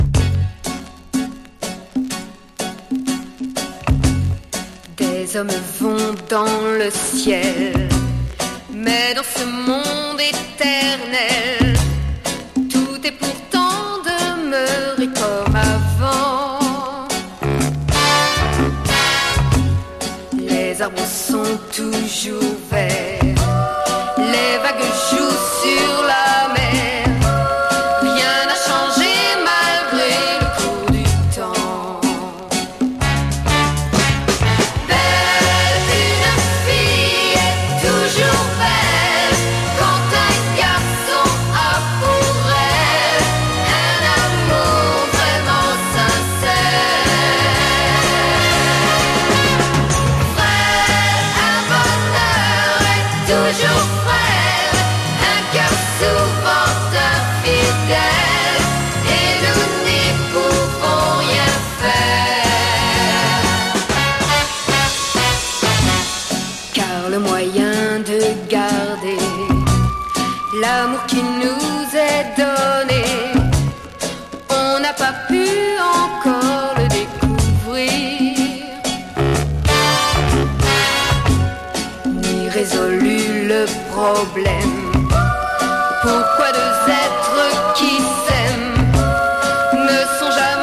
グルーヴィーなフレンチ・ソフトロック/ガール・ポップ・ソウル！